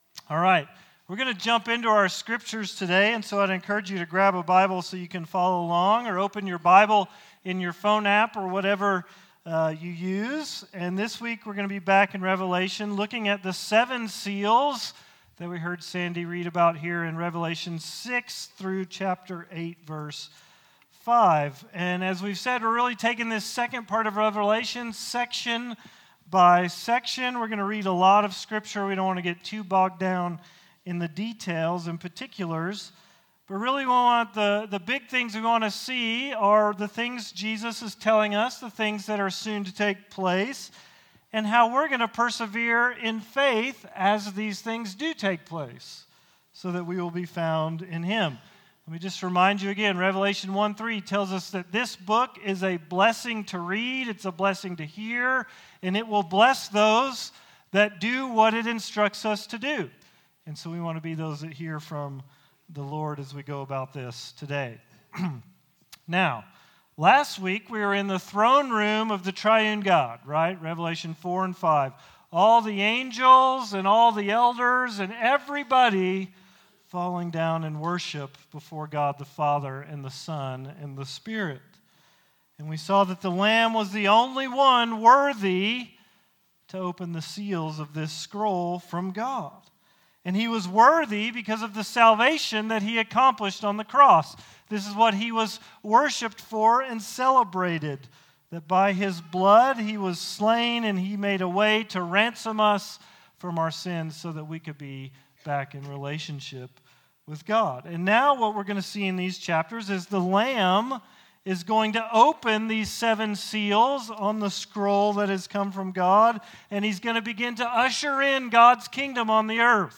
All Sermons - Risen Life Church